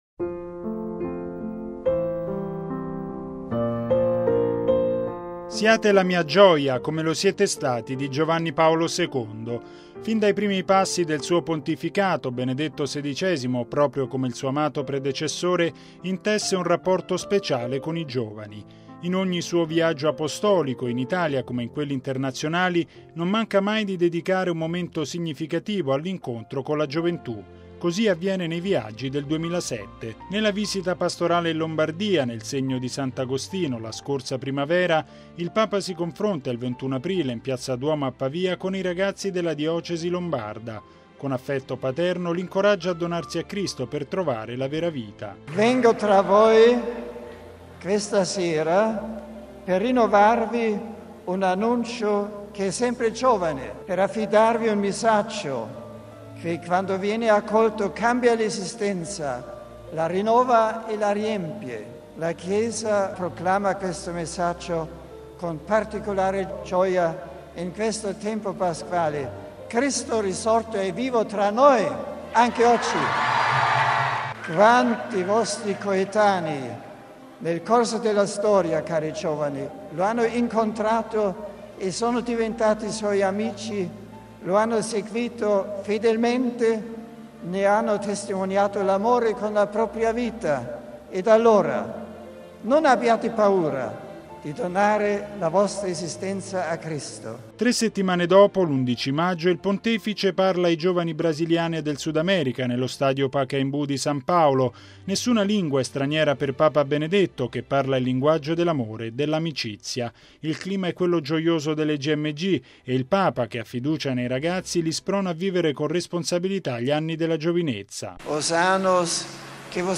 (Musica)